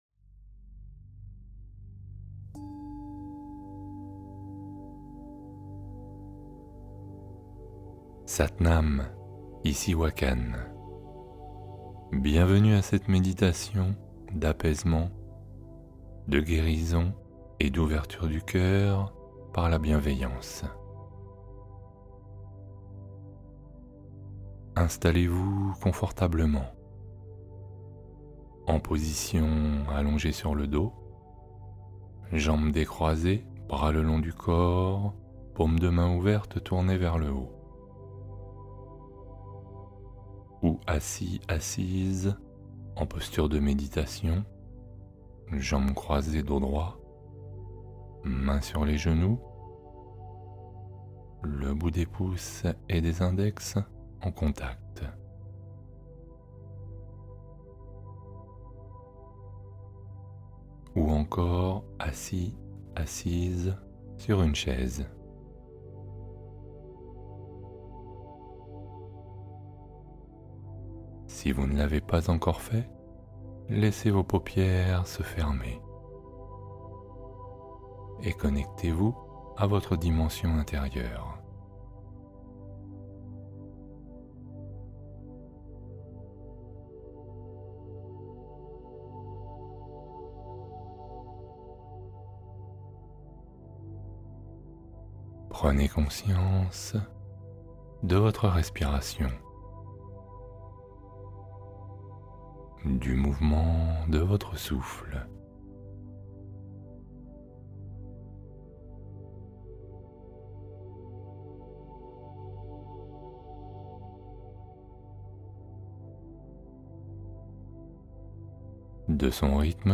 Guérison et ouverture du cœur : méditation bienveillante